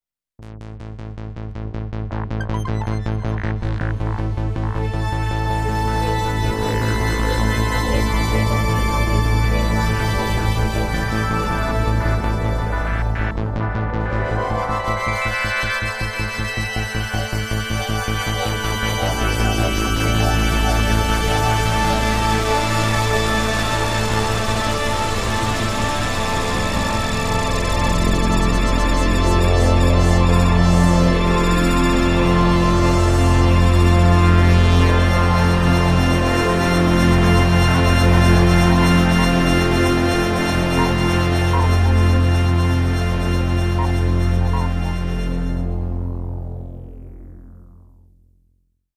Korg PS-3300 FS audio example